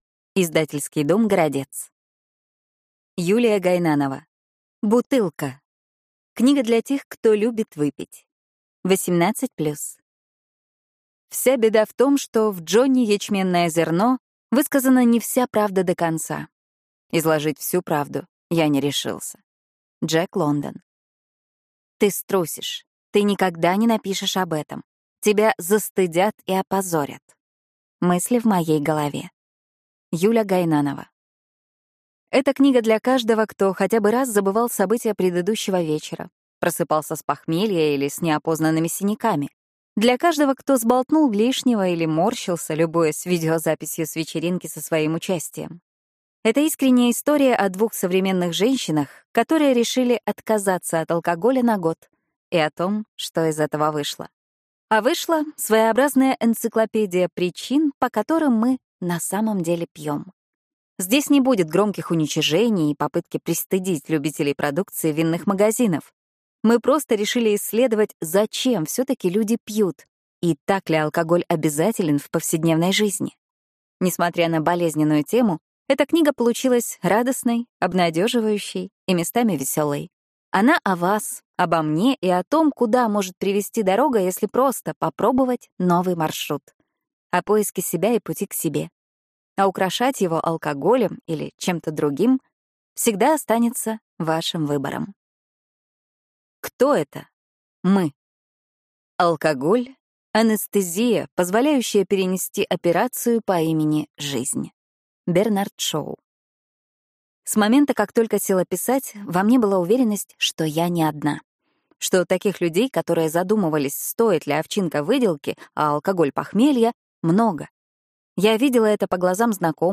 Аудиокнига Бутылка. Книга для тех, кто любит выпить | Библиотека аудиокниг